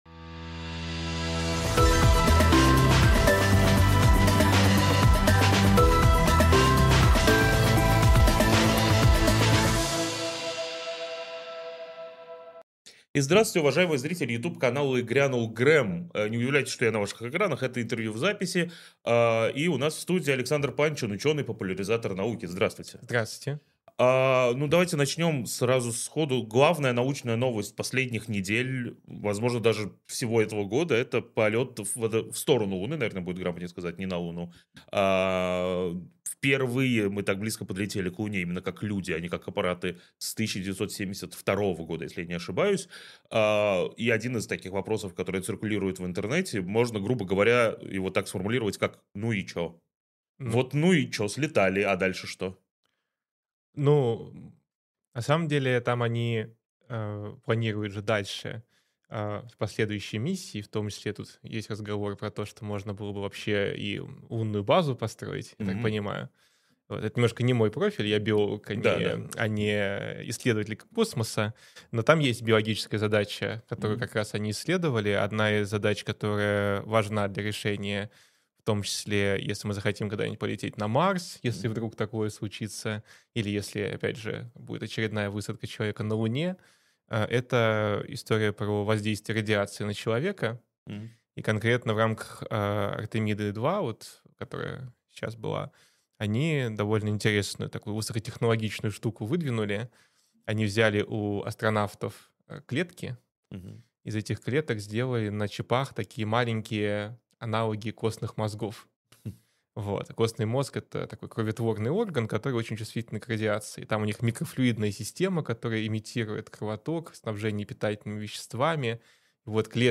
Александр Панчин биолог и популяризатор науки Эфир